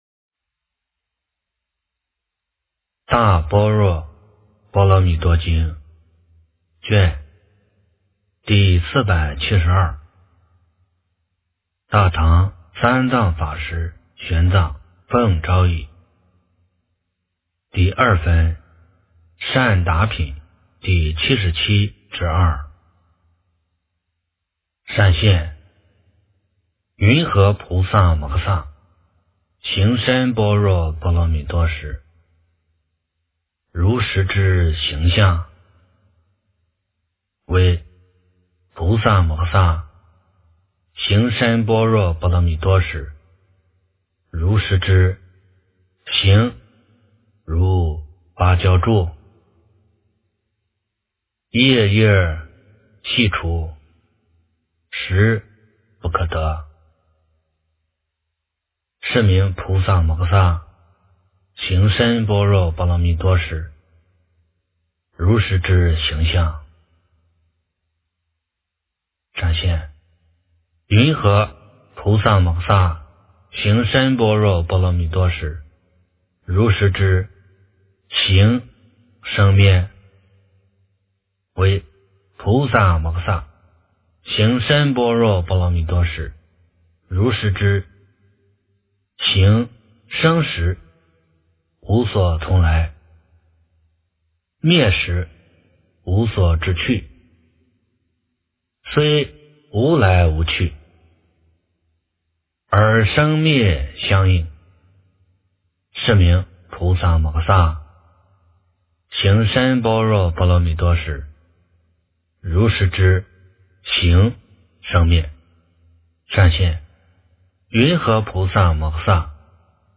大般若波罗蜜多经第472卷 - 诵经 - 云佛论坛